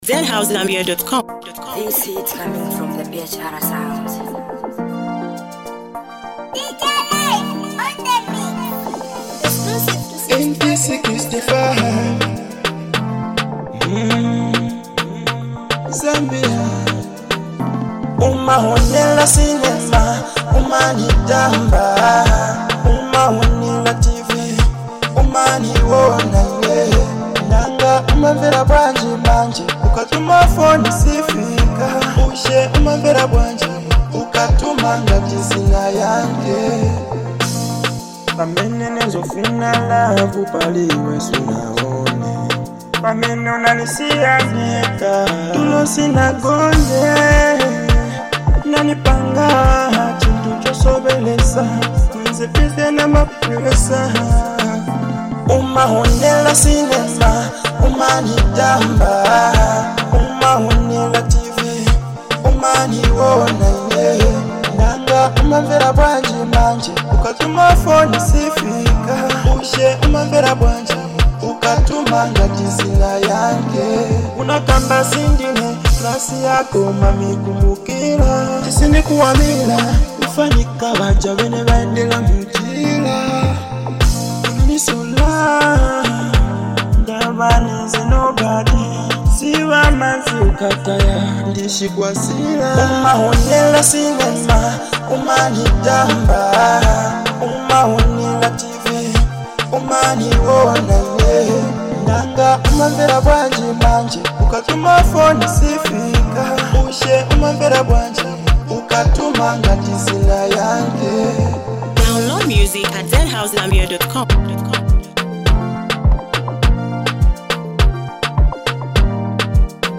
A smooth vibe